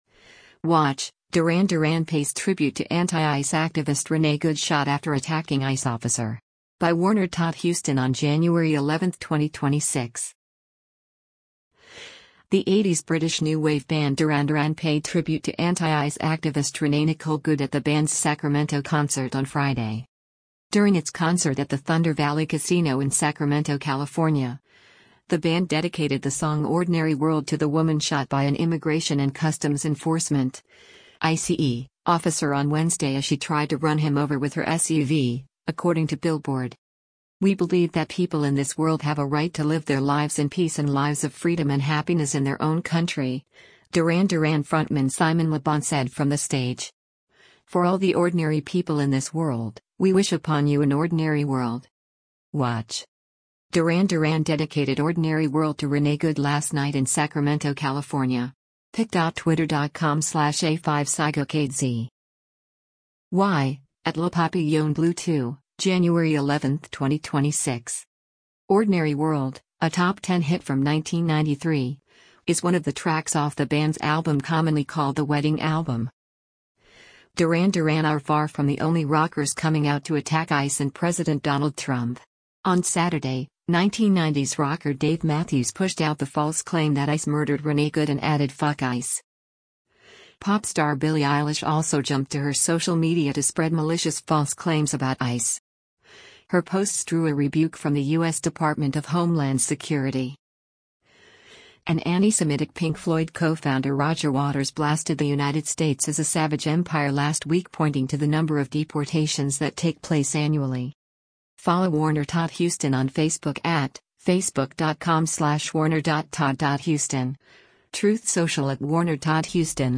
The 80s British New Wave band
concert on Friday